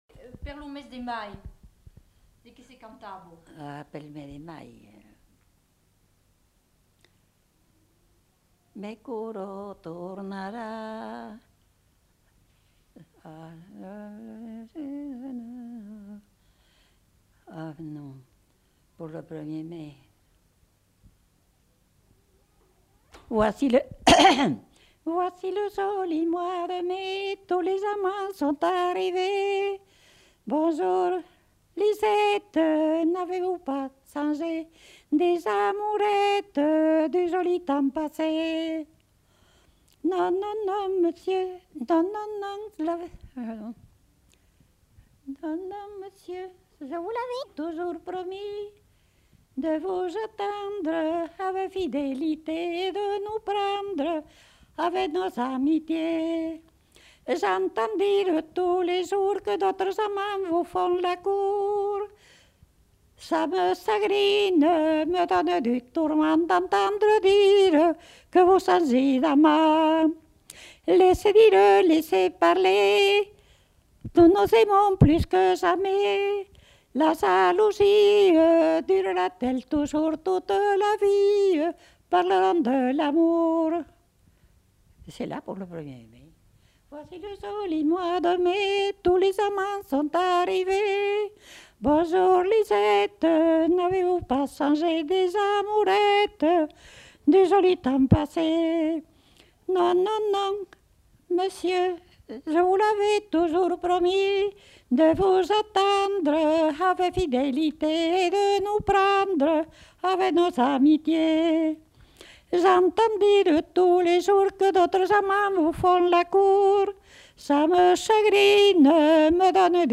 Aire culturelle : Haut-Agenais
Lieu : Condezaygues
Genre : chant
Effectif : 1
Type de voix : voix de femme
Production du son : chanté